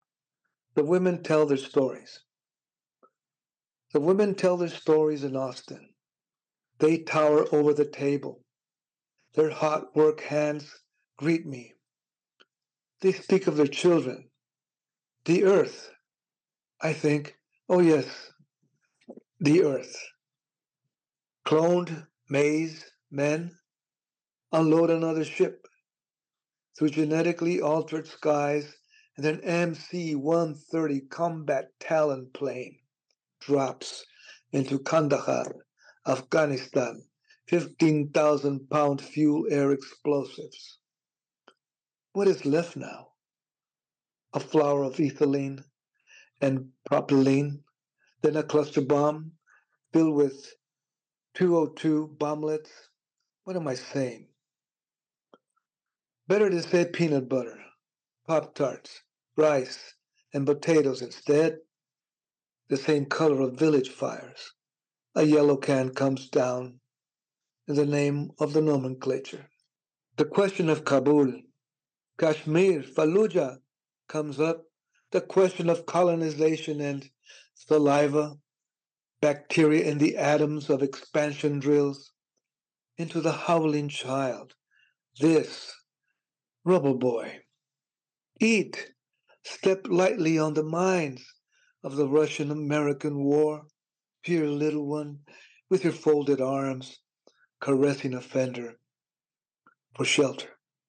Juan Felipe Herrera, twenty-first Poet Laureate of the United States and a 2024 MacArthur “genius grant” recipient, sat down with Library of America to read and discuss poems from Latino Poetry: The Library of America Anthology.